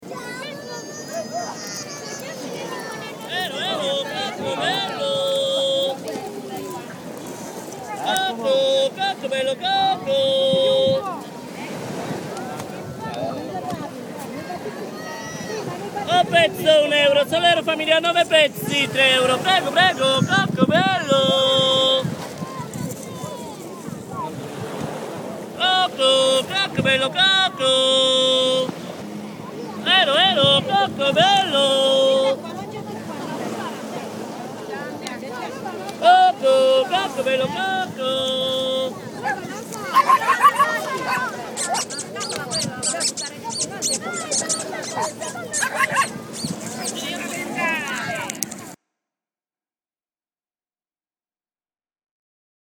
ki suvereno, doborovoljno in s smelim ter lahkotnim korakom vleče za sabo svoj voziček, v katerem ima naloženo brdo koščkov kokosa … model je bil interesanten ne le zaradi kulinaričnega firbca, ampak tudi iz oglaševalskega stališča, saj za razliko od plažnih ponujavcev kičeraja ni izbral taktitke direktnega težakanja z vztrajnim tiščanjem robe v fris potencialnega kupca, ampak se je poslužil tzv. jesiharske metode … se pravi, da je svojo oferto oznanjal z glasno vokalno interpretacijo enega in istega napeva … prisluhni: